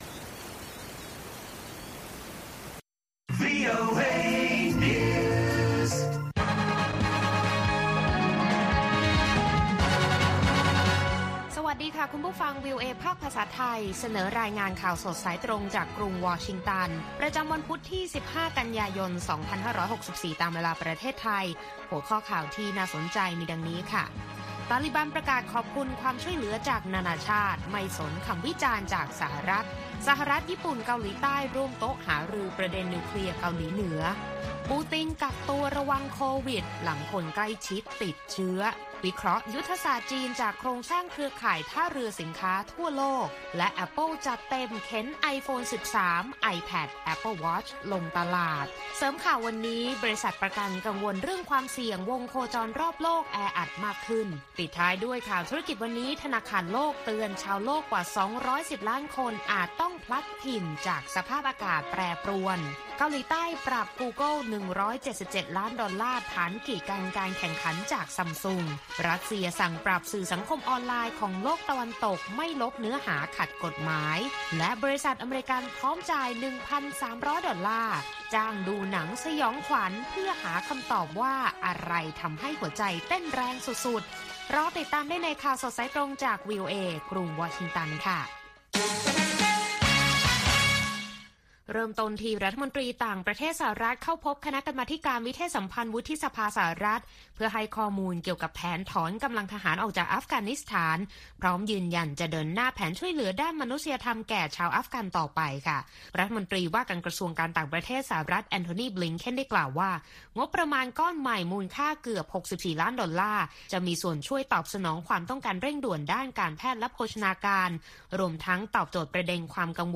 ข่าวสดสายตรงจากวีโอเอ ภาคภาษาไทย ประจำวัน พุธ ที่ 15 กันยายน 2564 ตามเวลาประเทศไทย